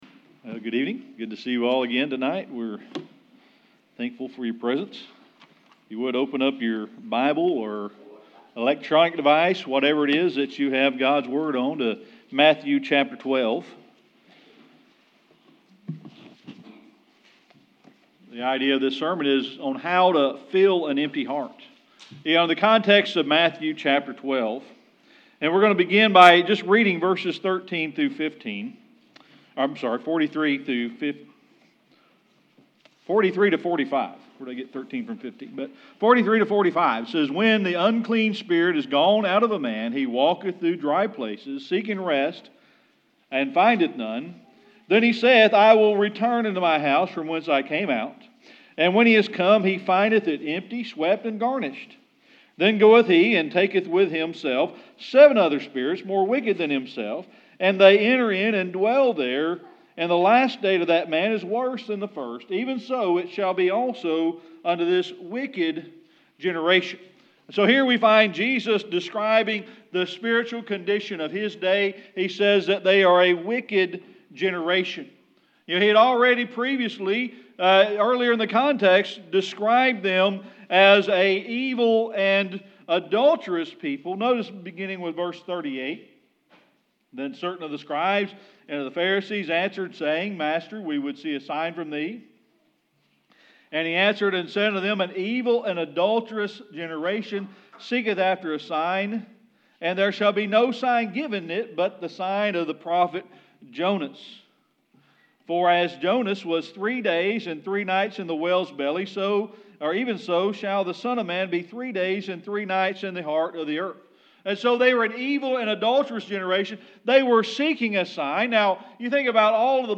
This sermon focuses on the dangers of an empty heart and how to fill an empty heart. It focuses on filling it with God's Word to prevent evil from taking over.